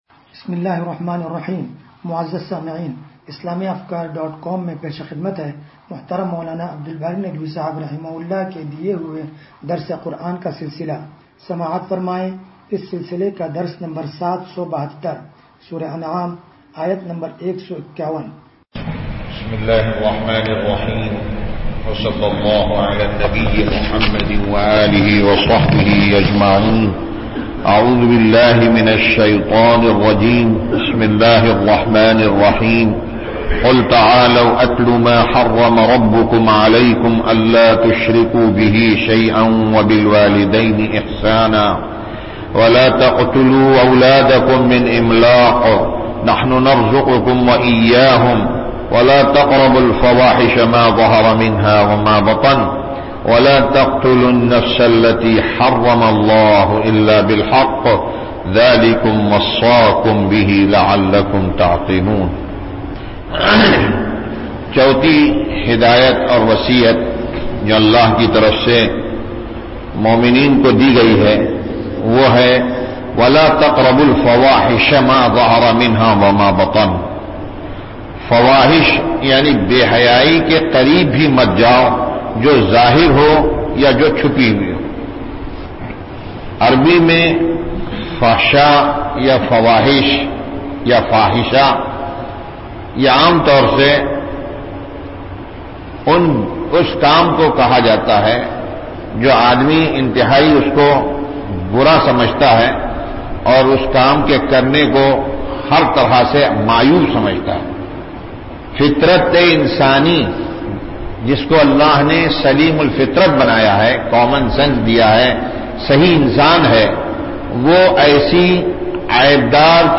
درس قرآن نمبر 0772